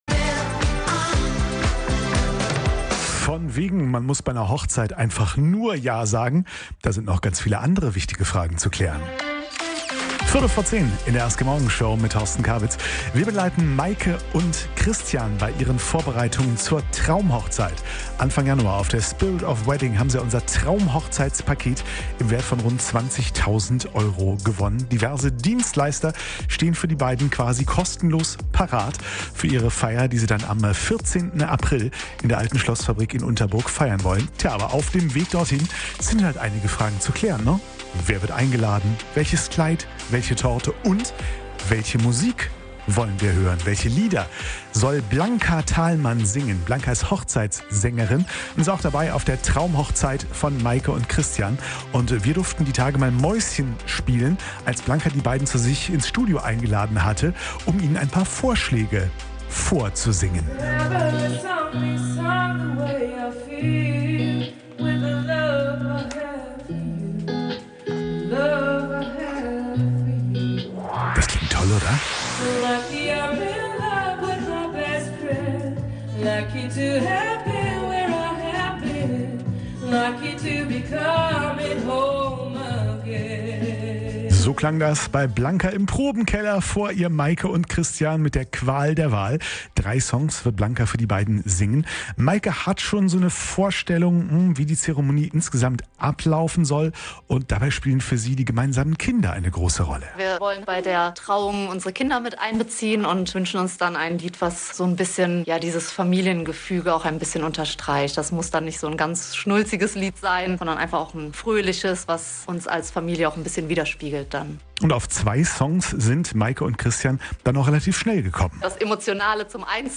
Hochzeitssängerin
zur Live-"Hörprobe" im Probenkeller